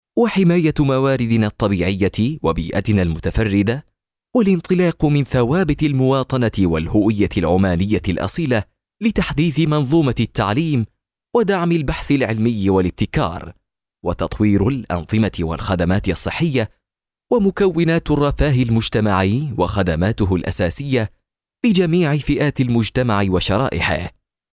特点：轻快活力 大气浑厚 稳重磁性 激情力度 成熟厚重
阿拉伯语男女样音